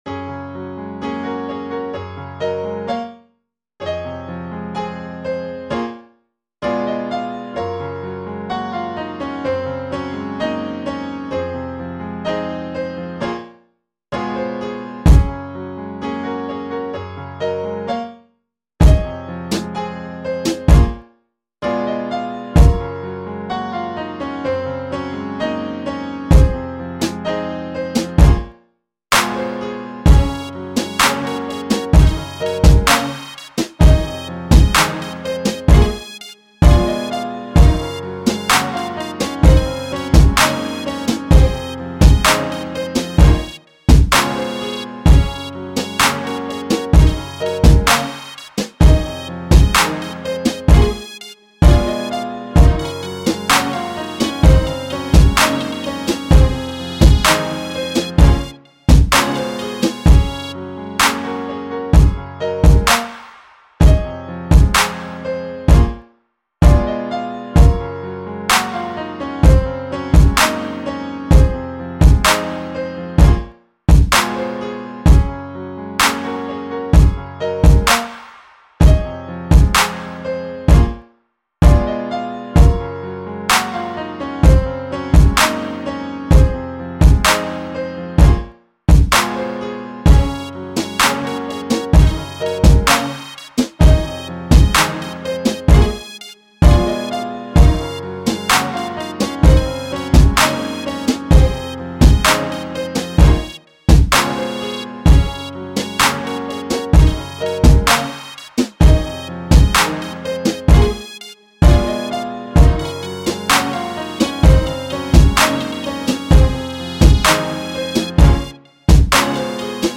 Intro - 1절 - 코러스 - 2절 - 코러스 - 브릿지(3분쯤부터) - 코러스
알앤비 한번 만들어봤습니다..
-피아노랑 전자음만 들리는데 PAD포함해서 악기가 한두개만 더 있었으면 진짜 다 쓸었을 거란 생각도 듭니다.